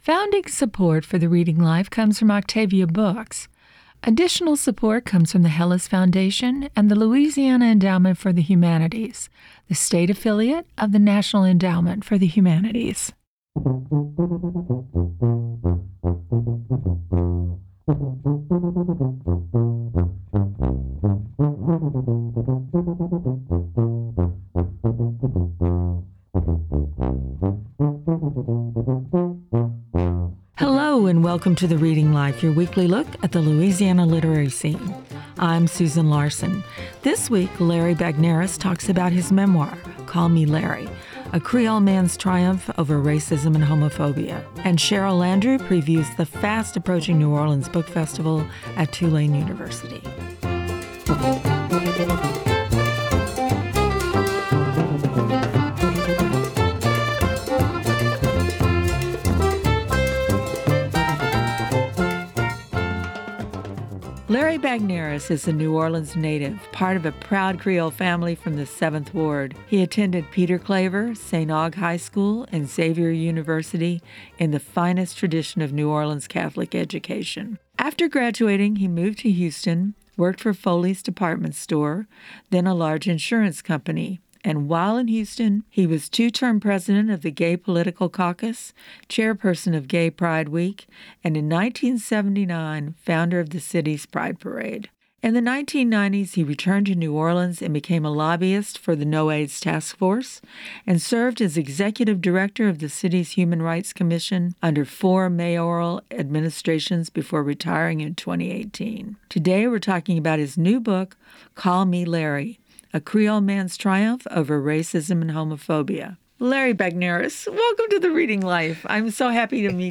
Hear celebrated and up-and-coming authors read excerpts from new books